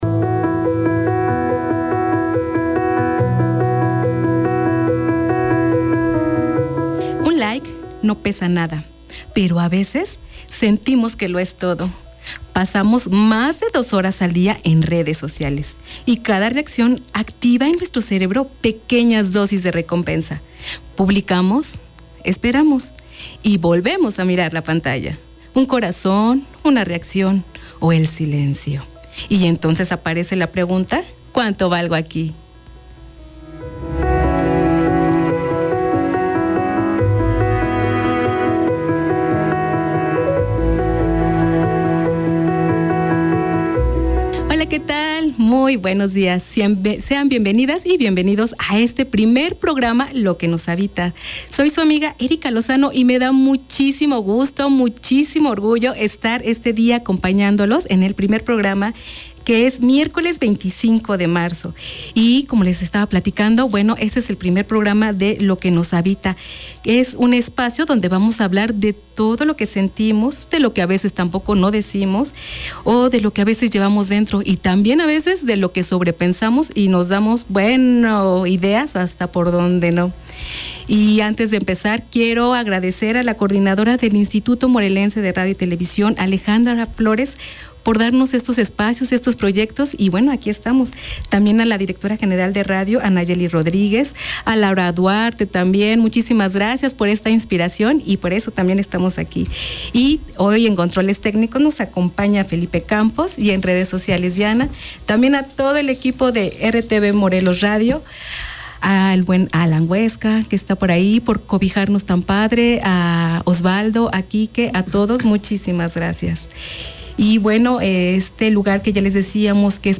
Lo que nos habita es un espacio radiofónico de conversación y reflexión sobre el mundo interior de las personas. A través de diálogos cercanos y humanos, el programa explora emociones, identidad, relaciones, resiliencia y los procesos que atravesamos a lo largo de la vida.